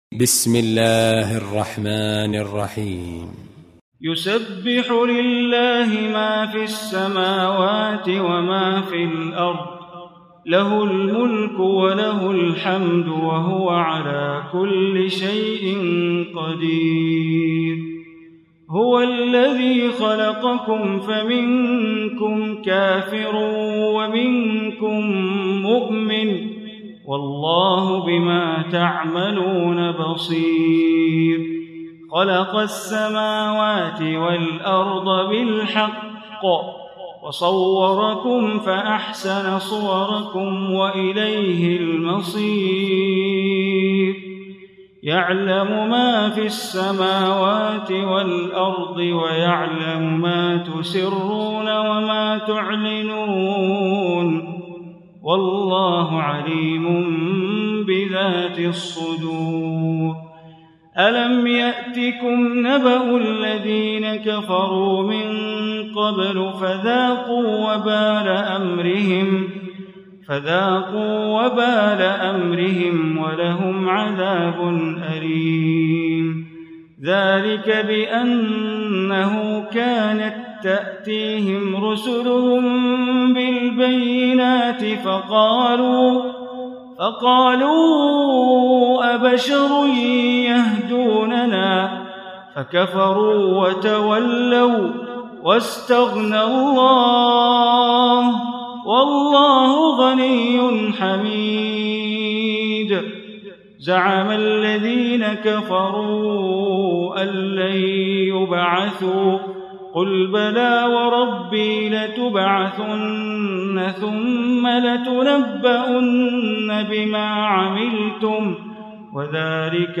Surah Taghabun Recitation by Sheikh Bandar Baleela
Surah Taghabun, listen online mp3 tilawat / recitation in Arabic recited by Imam e Kaaba Sheikh Bandar Baleela.